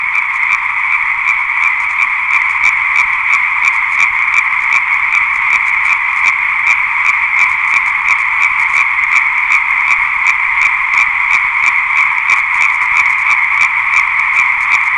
Immerso in un concerto frastornante!
tutti gli altri maschi cantavano in acqua
Vi scrivo in diretta dal Laghetto: 130 raganelle contate (mi son fermato poi...) e ben 37 dal fenotipo marrone ( e 2 grigie)... Praticamente 1/4!
Immerso in un concerto frastornante! Raganelle 1.wav